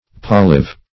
polyve - definition of polyve - synonyms, pronunciation, spelling from Free Dictionary Search Result for " polyve" : The Collaborative International Dictionary of English v.0.48: Polyve \Pol"yve\, n. [See Polive .]
polyve.mp3